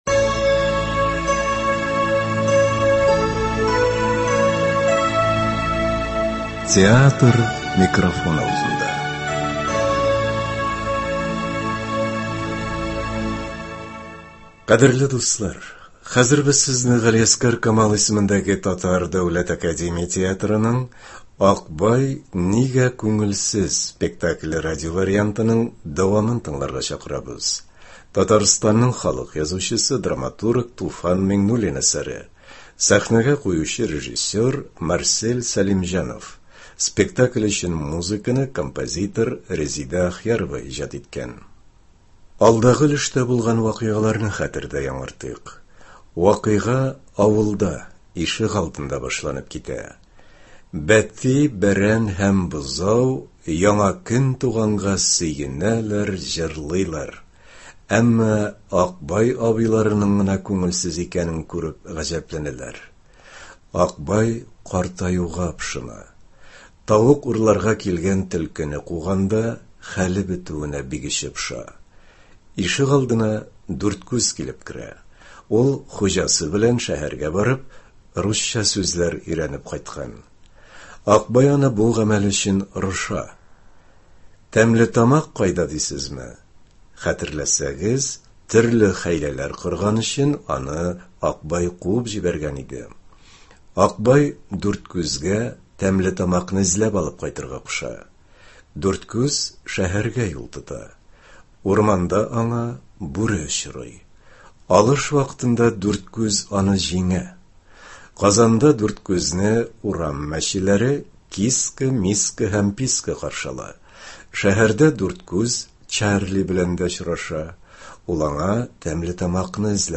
Бүген без сезнең игътибарга Г.Камал исемендәге Татар Дәүләт академия театрының “Акбай нигә күңелсез” спектакленең радиовариантын тәкъдим итәбез.